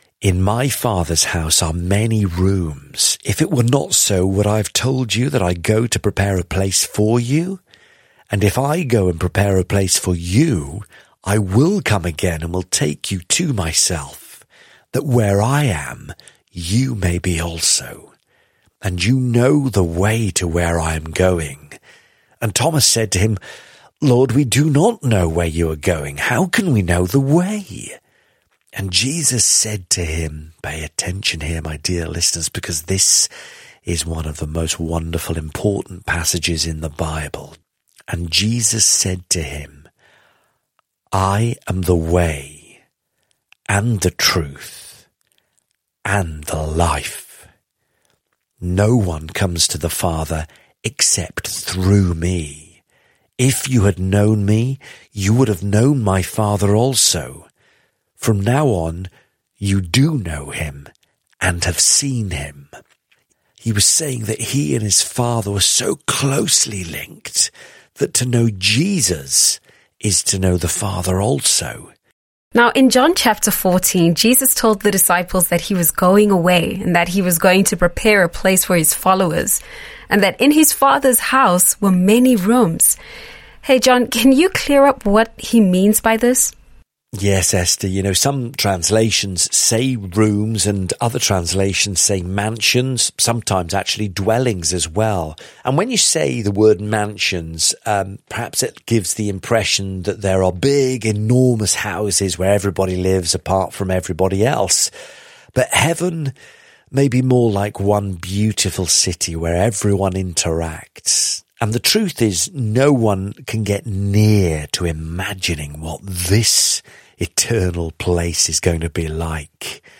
teachers on the daily Bible audio commentary